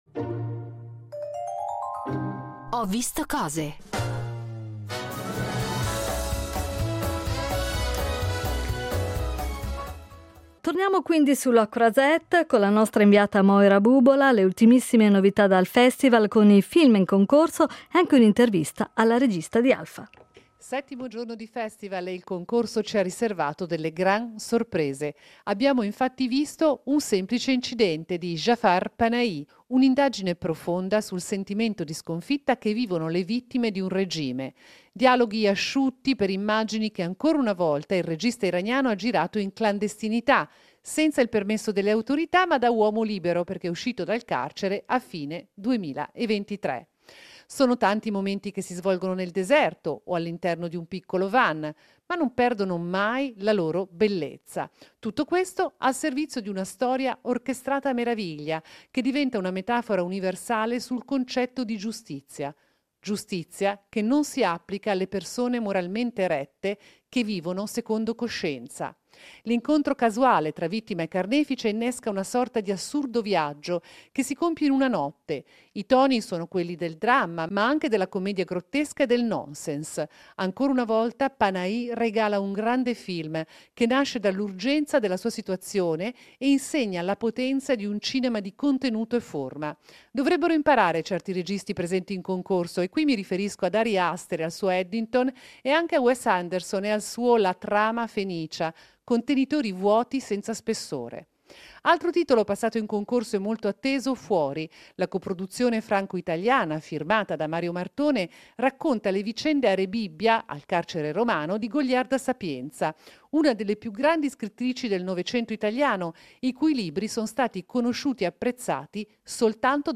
“Ho visto cose”, da Cannes